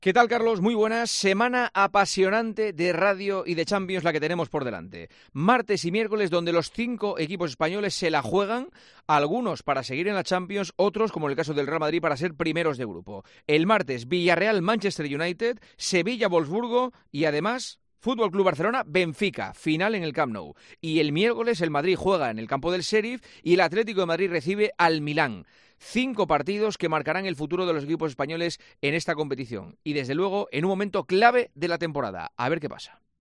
Juanma Castaño analiza la actualidad deportiva en 'Herrera en COPE'